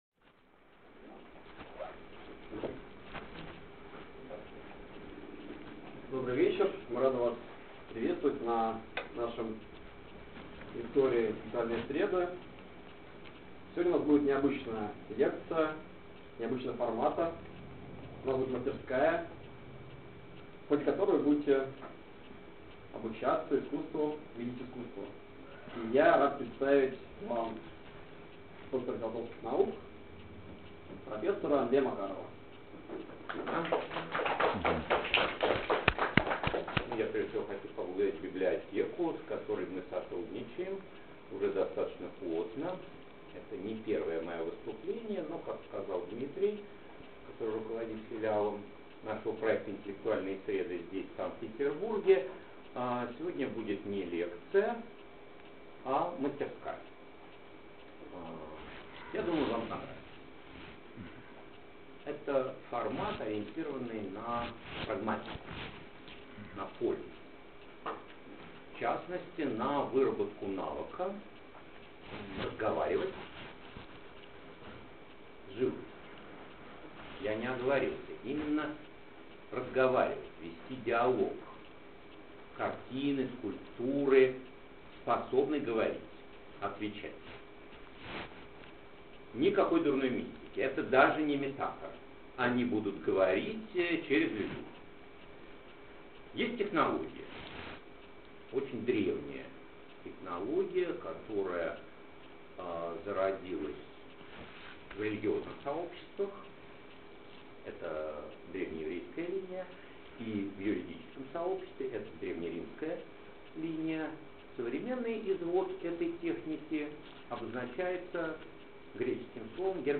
Аудиокнига Искусство задавать вопросы шедеврам искусства | Библиотека аудиокниг